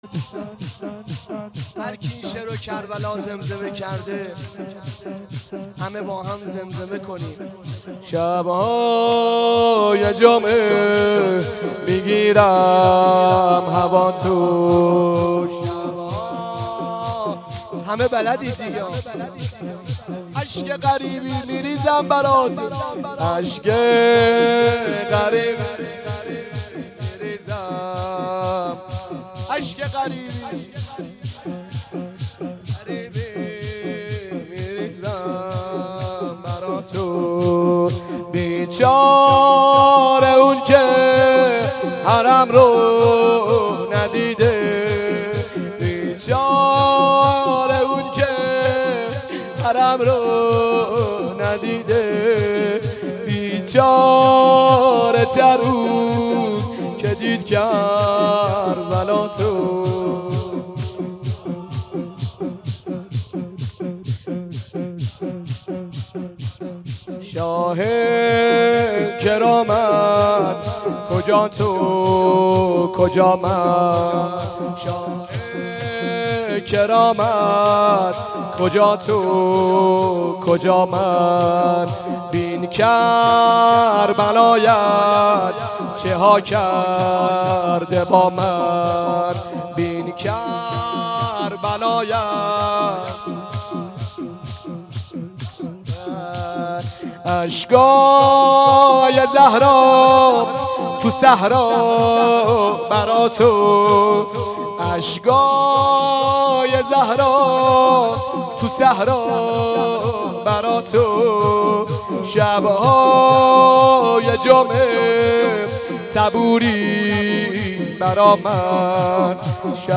خیمه گاه - هیئت متوسلین به حضرت ابالفضل العباس (علیه السلام) - مجلس عزاداری